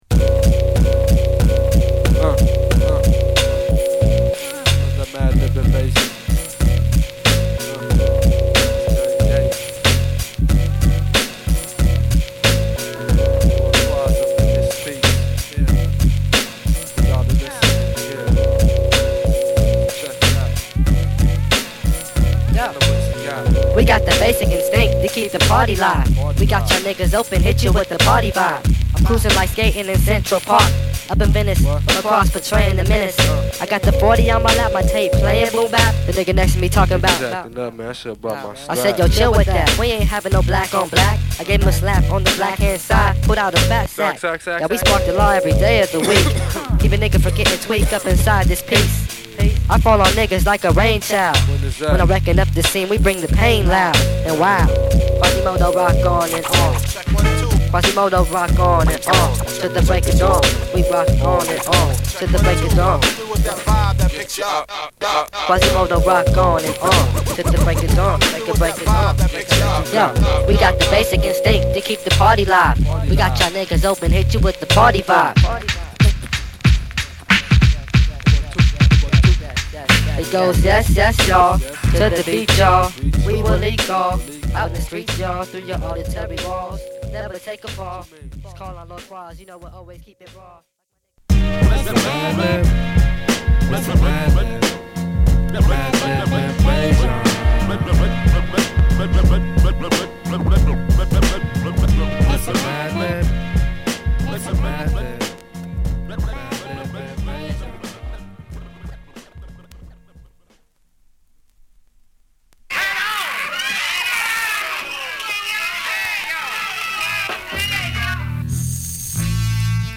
独特の荒い鳴りのビートに、お馴染みの変声ラップ。